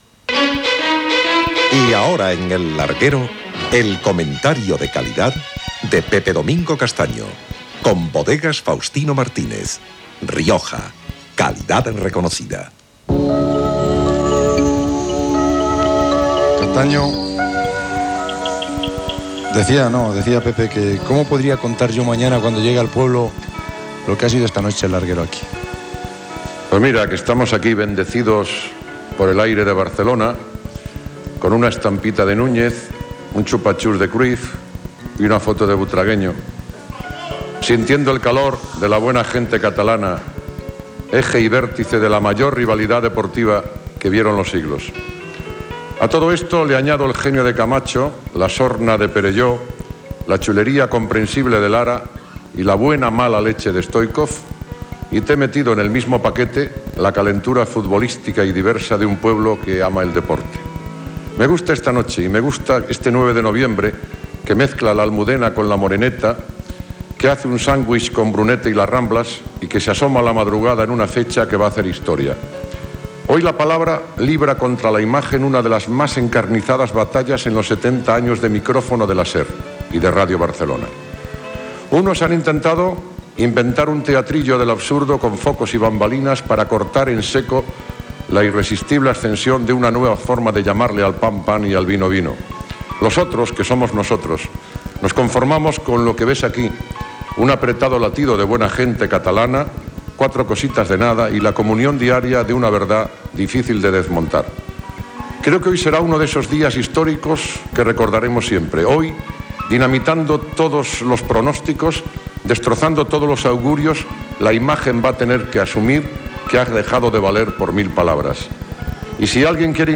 Esportiu
Programa fet des de Barcelona, al Palau Macaya del Centre Cultural la Caixa.